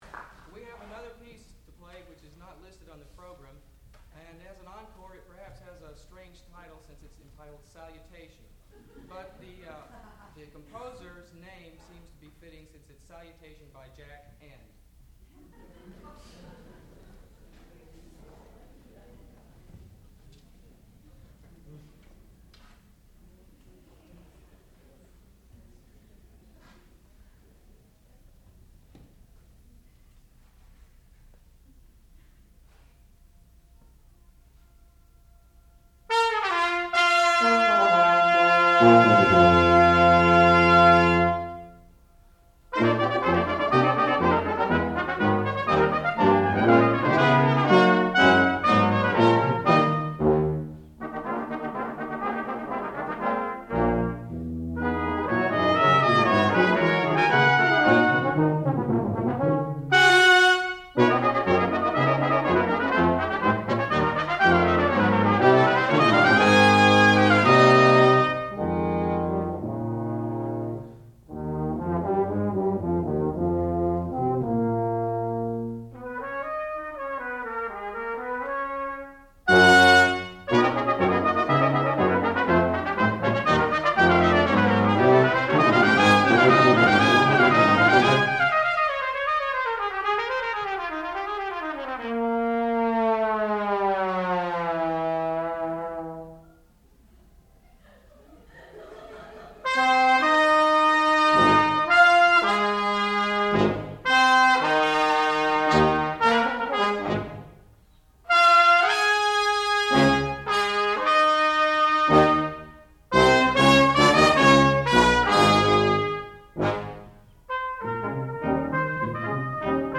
sound recording-musical
classical music
trombone
trumpet
horn